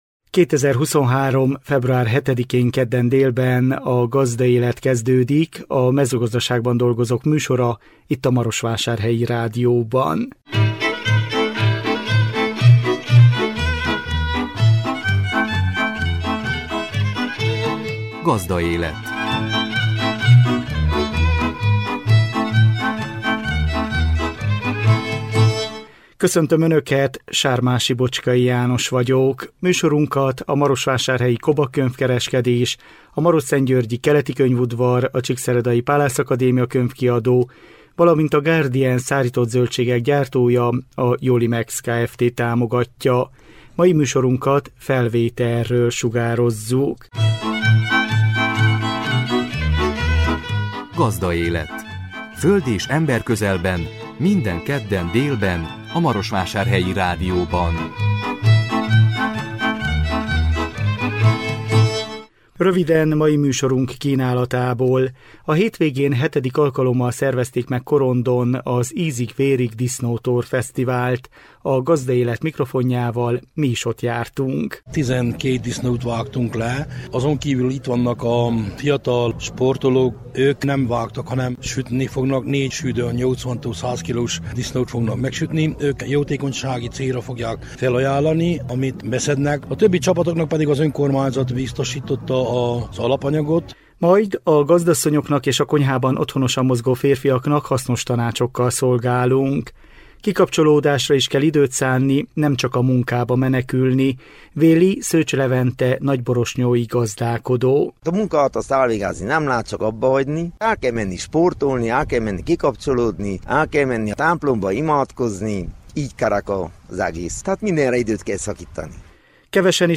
A 2023 február 7-én jelentkező műsor tartalma: A hétvégén VII. alkalommal szervezték meg Korondon az “Ízig-vérig” disznótor fesztivált. A Gazdaélet mikrofonjával mi is ott jártunk. Majd a gazdasszonyoknak és a konyhában otthonosan mozgó férfiaknak hasznos tanácsokkal szolgálunk.